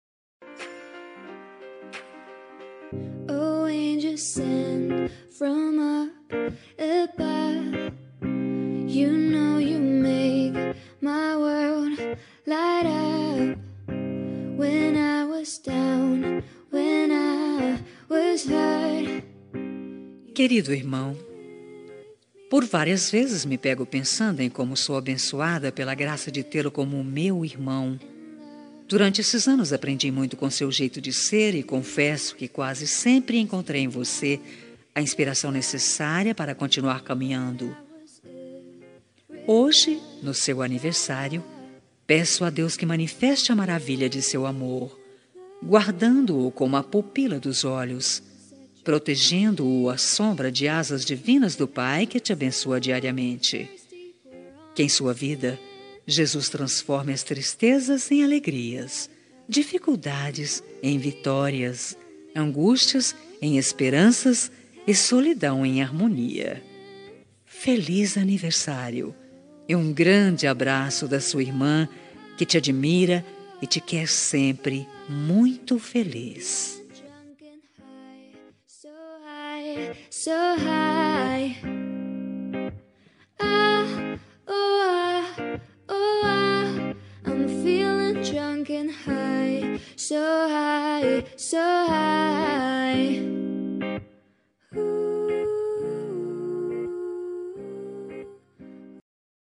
Telemensagem de Aniversário de Irmão – Voz Feminina – Cód: 4224 – Linda